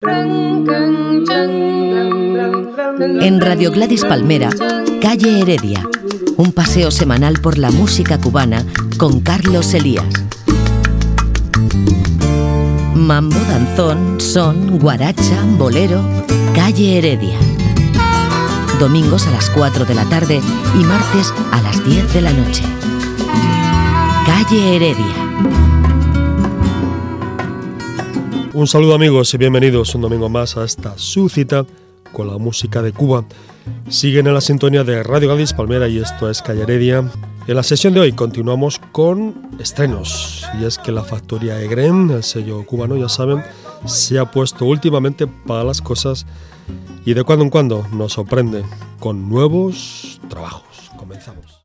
Careta del programa, presentació i estrena musical del segell cubà Egrem
Musical
FM